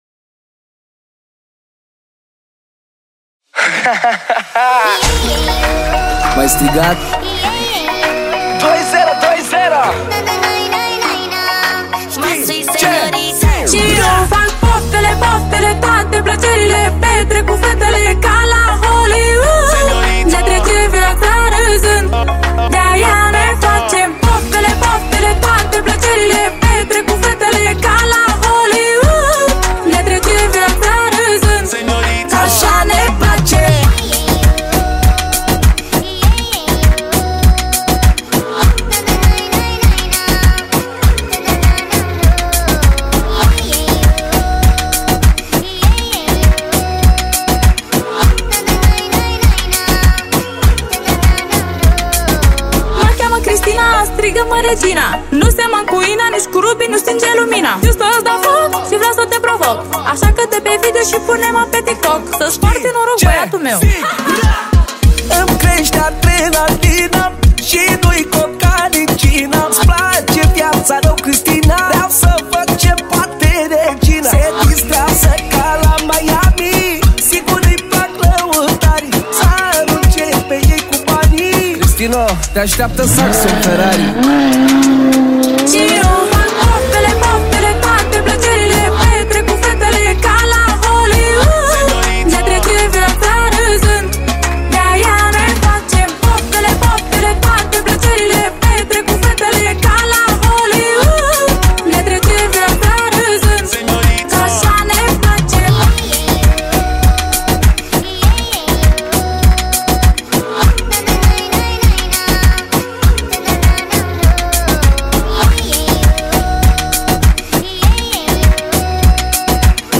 Data: 10.10.2024  Manele New-Live Hits: 0